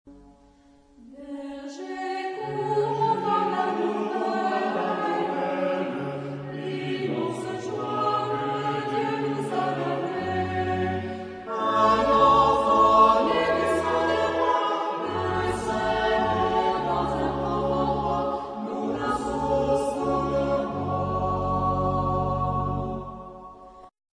Epoque : 18ème s.
Genre-Style-Forme : noël ; Sacré
Type de choeur : SAH  (3 voix mixtes )
Tonalité : fa dièse mineur